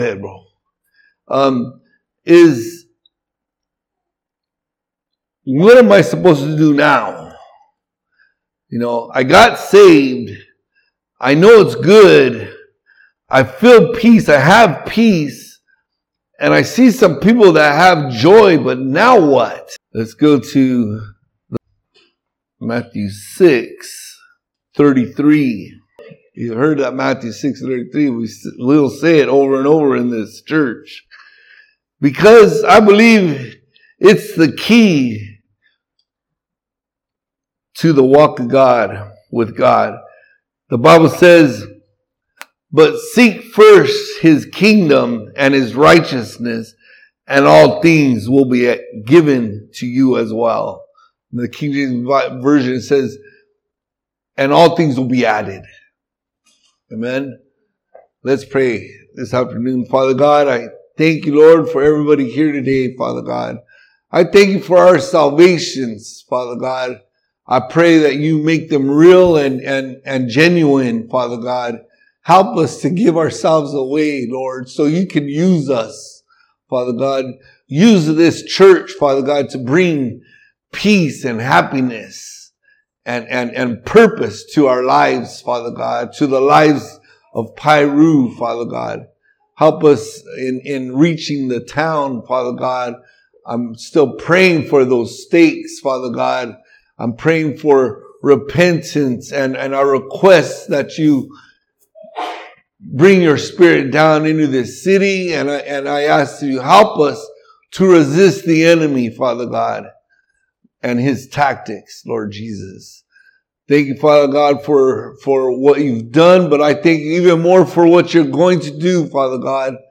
All Sermons Matthew 6 March 26